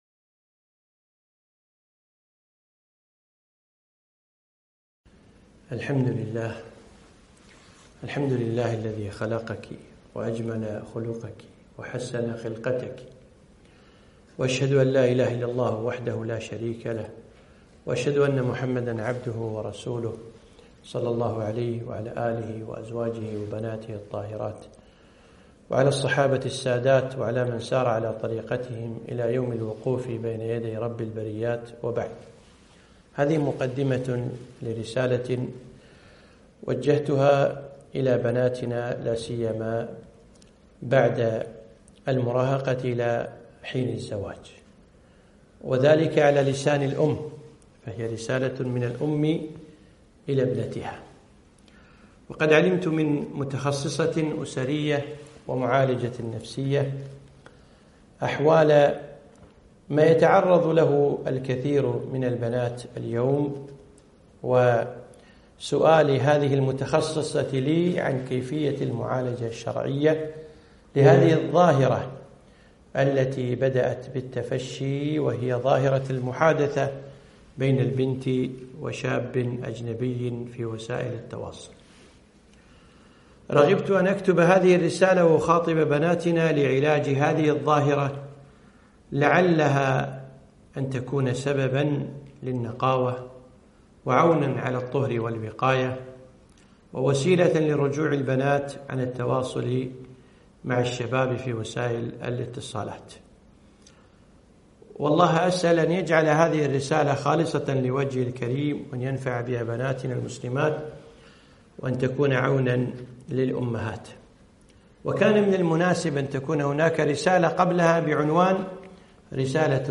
محاضرة - يا ابنتي .. رسالة من أم لابنتها